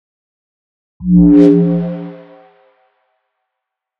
Âm thanh chuyển cảnh tiếng Bass (ngắn)
Thể loại: Âm thanh chuyển cảnh
Description: Âm thanh chuyển cảnh tiếng Bass (ngắn) là hiệu ứng tiếng bass ngắn ngân vang, có thể sử dụng tiếng bass ngắn này làm âm thanh chuyển cảnh, hiệu ứng tiếng bass ngắn là âm trầm mang chất lượng âm thanh tốt và tròn trịa.
Am-thanh-chuyen-canh-tieng-bass-ngan-www_tiengdong_com.mp3